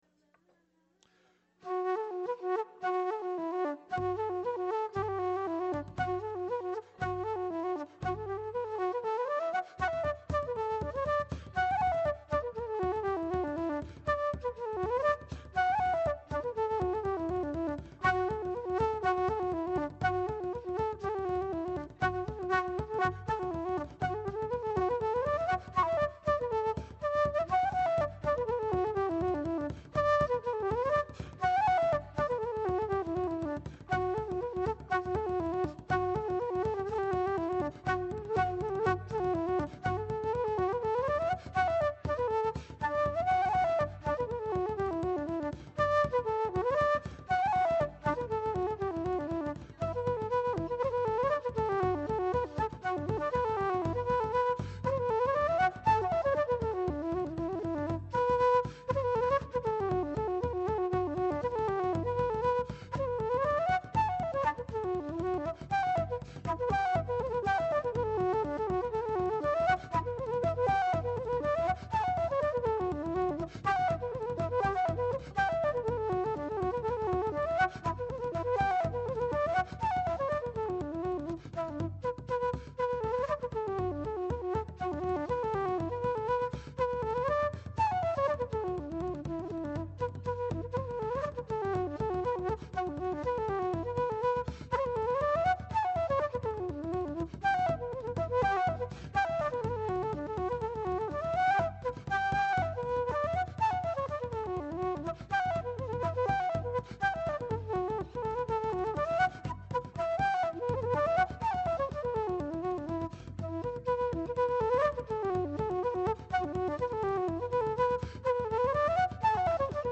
Live audio recordings
(Flute and Vocals)
[The Jigs are played with my 14″ Metloef, the Reels and Songs with my 18″ TrHed.]